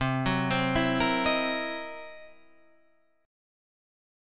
C7#9b5 chord